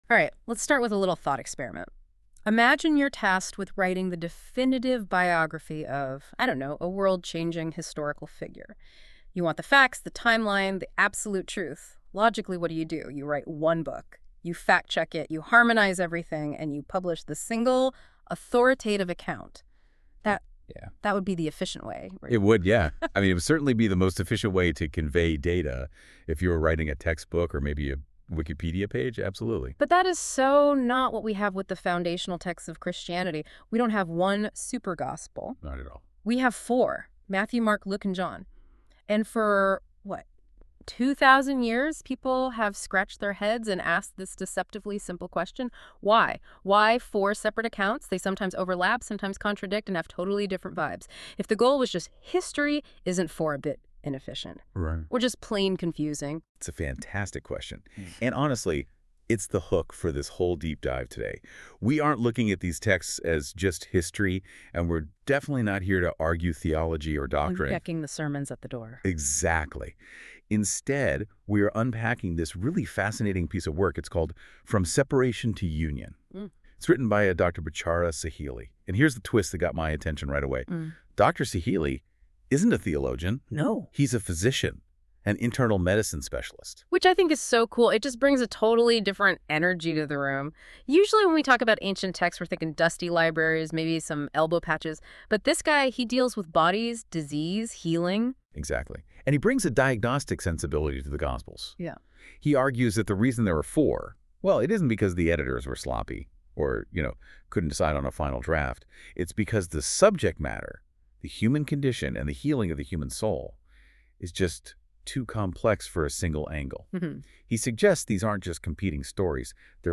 Deep Dive Audio Overview